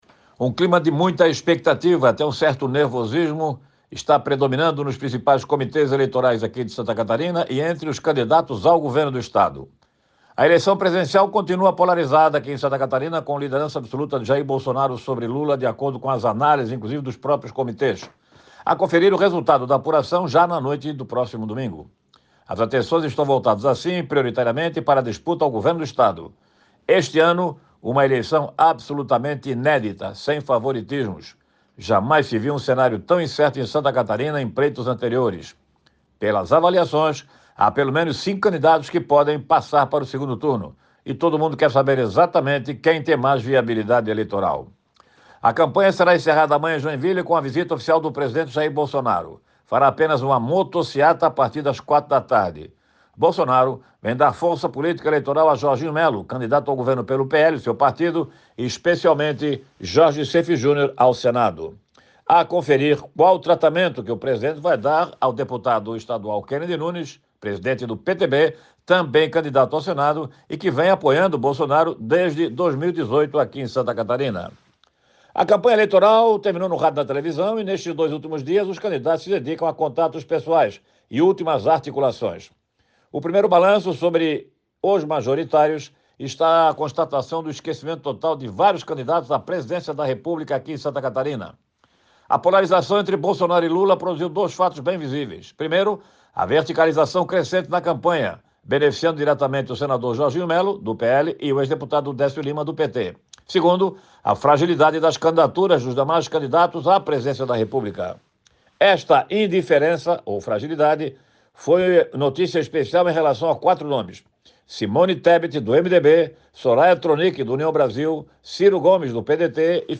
Jornalista ressalta a indefinição de um possível segundo turno e a vinda do atual presidente Jair Bolsonaro ao Estado